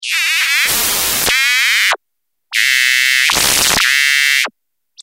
嗡嗡叫的口音循环
描述：有节奏的蜂鸣噪音脉冲FX循环。 使用Alesis AirSynth制作。